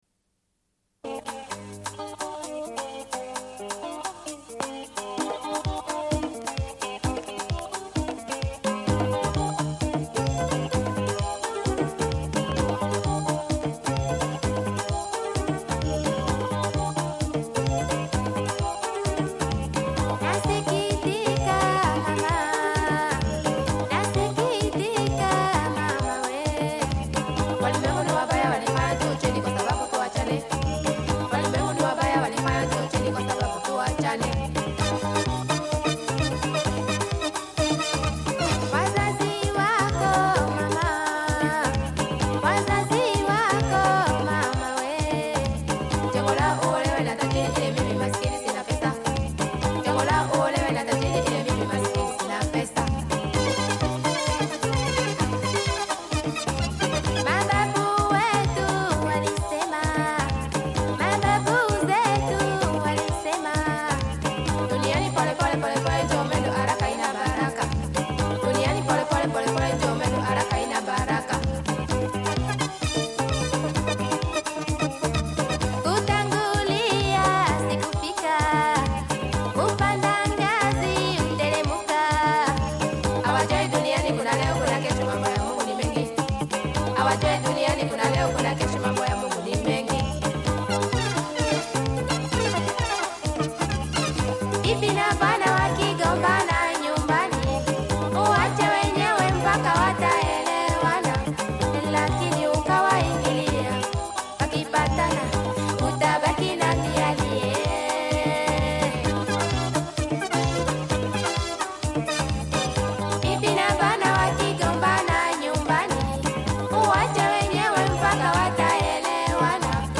Une cassette audio, face B28:59